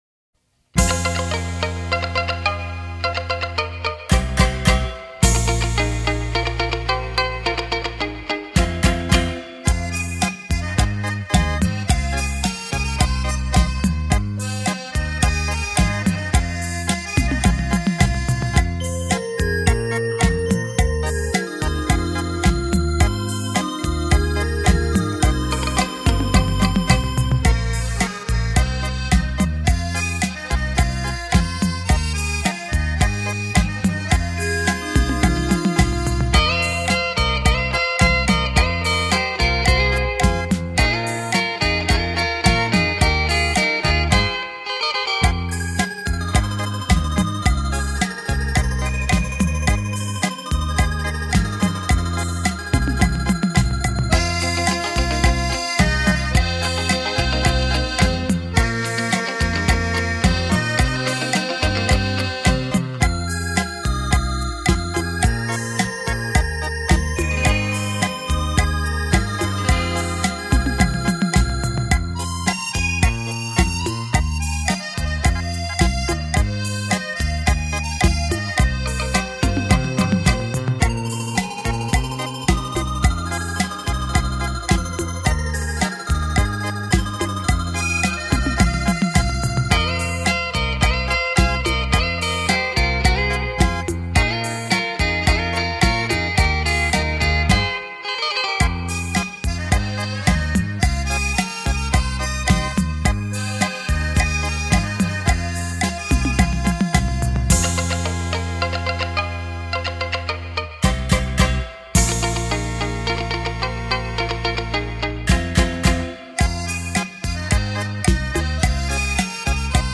超级立体音场环绕
沉浸在这感性的旋律里